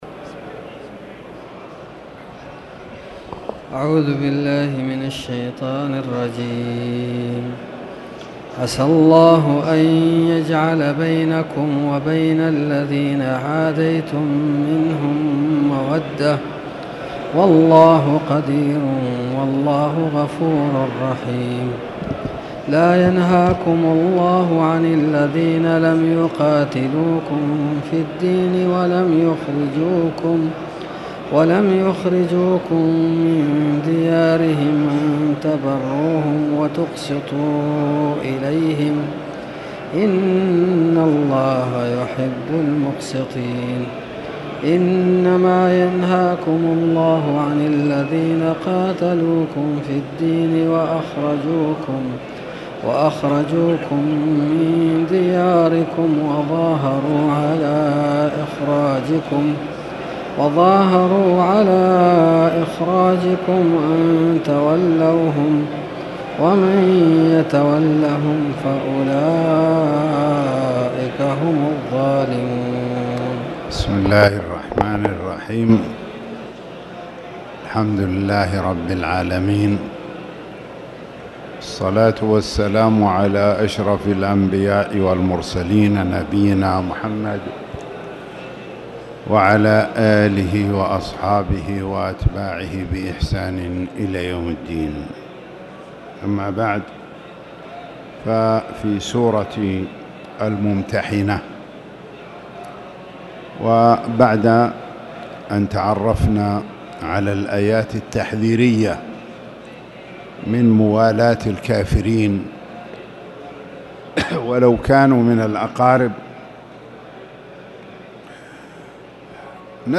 تاريخ النشر ٥ ربيع الأول ١٤٣٨ هـ المكان: المسجد الحرام الشيخ